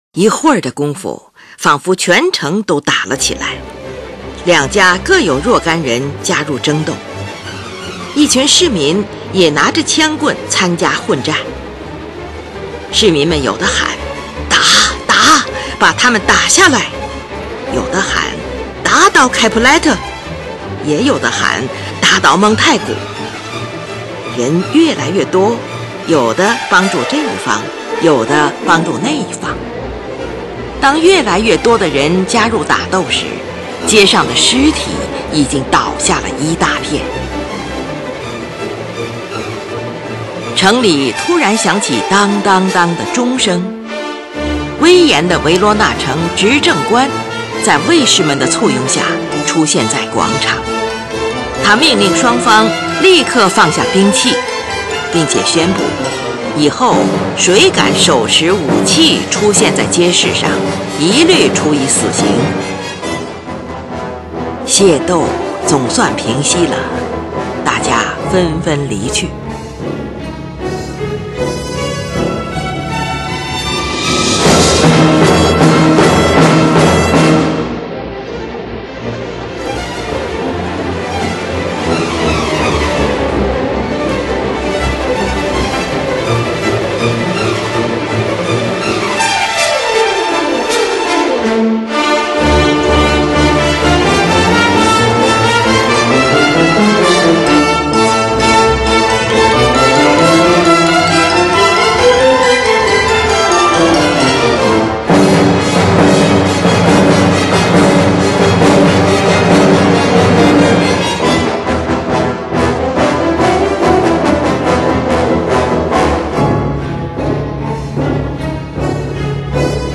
三幕芭蕾舞剧
这个主题的节奏坚定有力、呆板阴暗而又庄严有力。